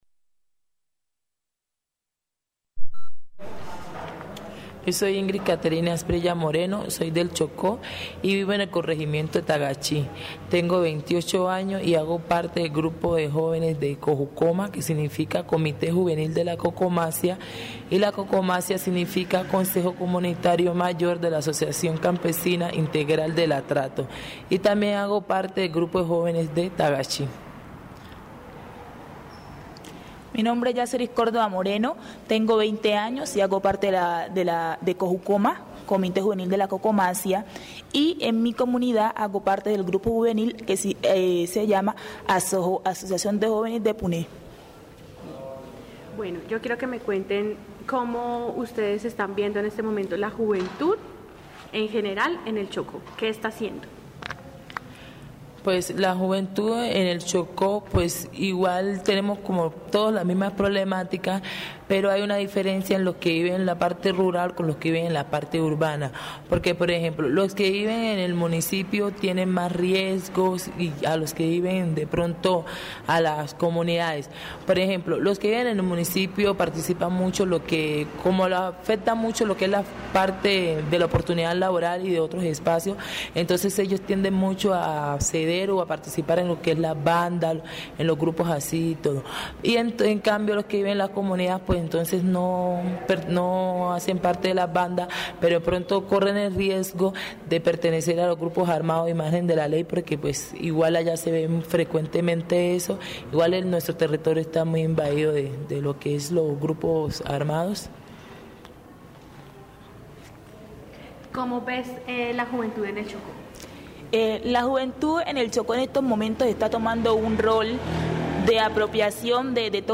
This radio program features an interview with various youth leaders from Chocó and Amazonas, representing different organizations and community collectives. The discussion addresses key challenges faced by young people in both regions, including the lack of job opportunities, the presence of armed groups in rural communities, and the need to strengthen youth participation in decision-making processes.